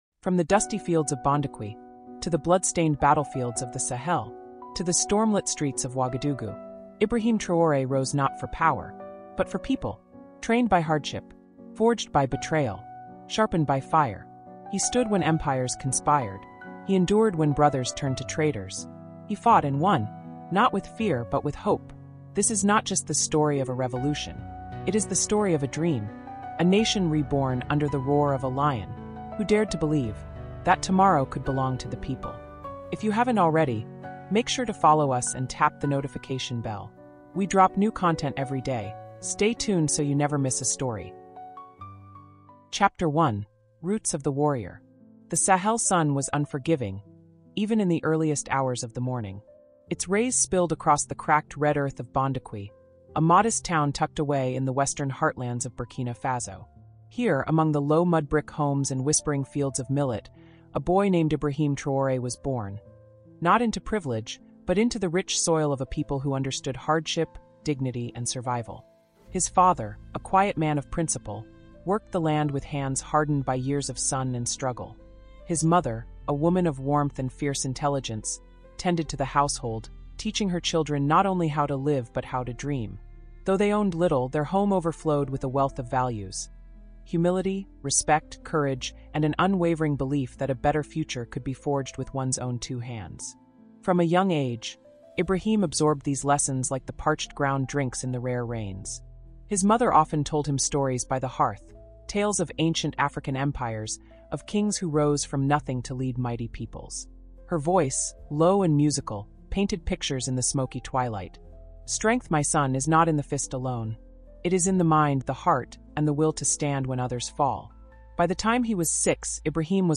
Captain Ibrahim Traoré: africa cultural diplomacy| Audiobook